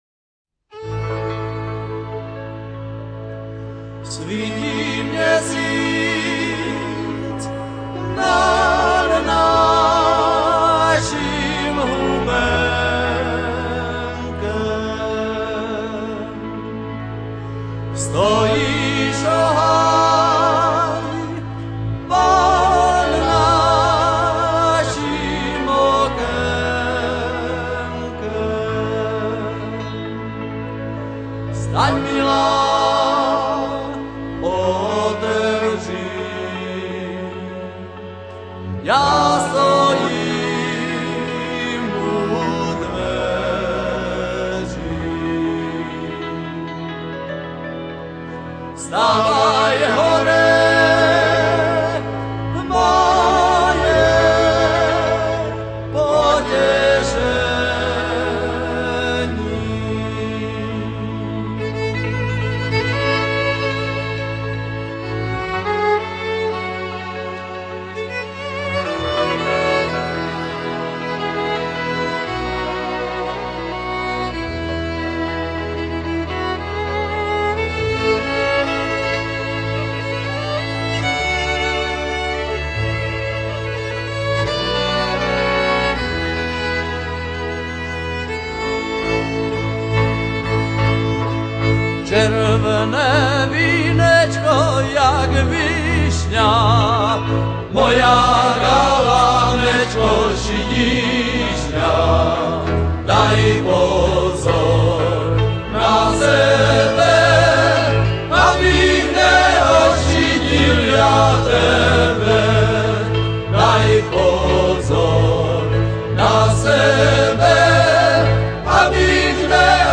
1. hlas zvuková nahrávka
Kategorie: Rhytm&Blues